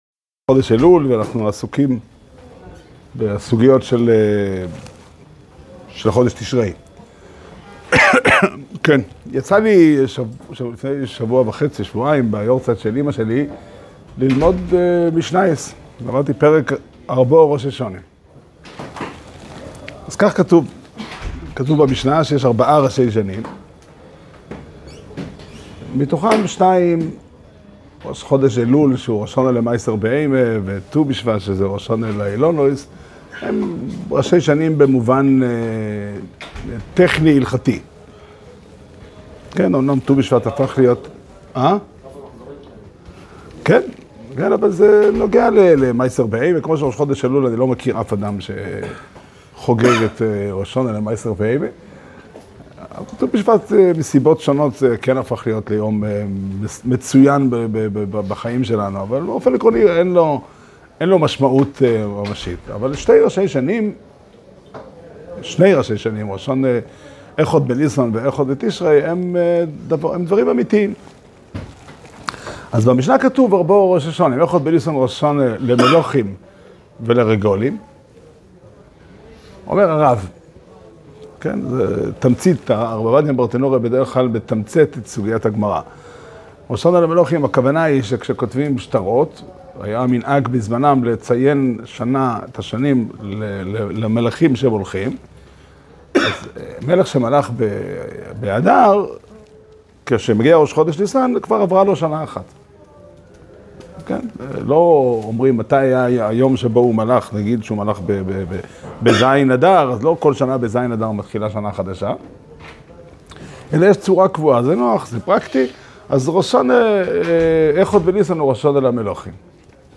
שיעור שנמסר בבית המדרש פתחי עולם בתאריך ב' אלול תשפ"ד